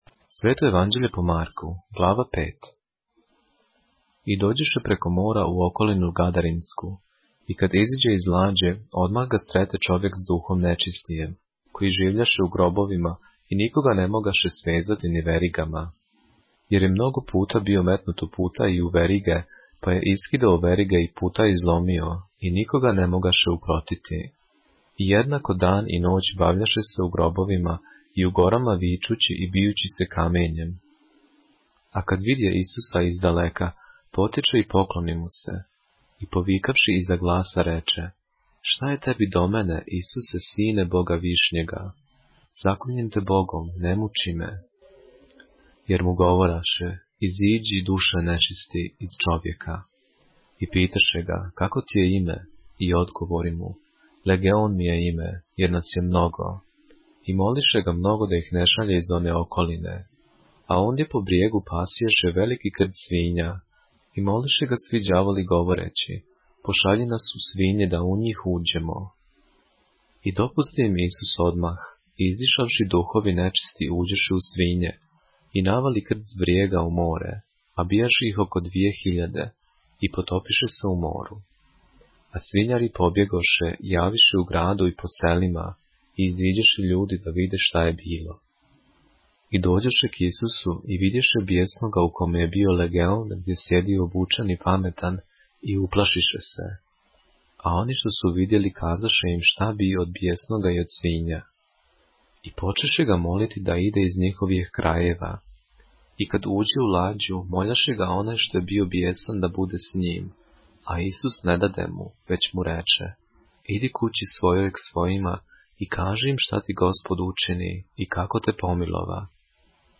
поглавље српске Библије - са аудио нарације - Mark, chapter 5 of the Holy Bible in the Serbian language